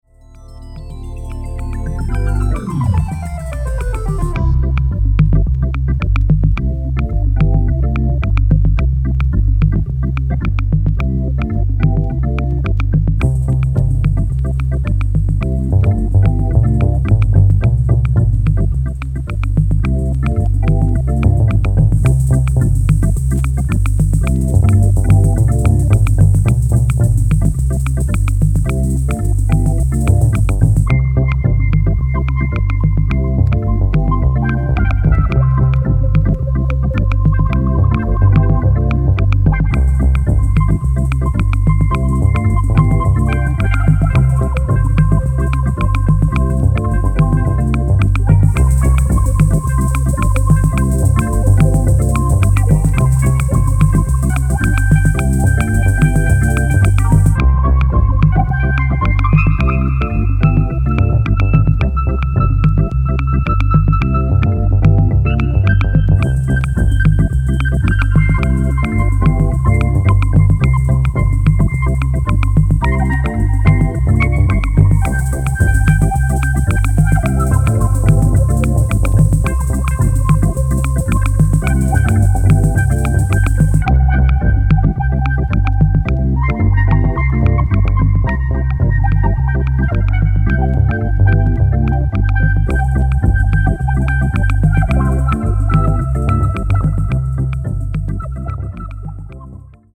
キーワード：電子音　ライブラリー　即興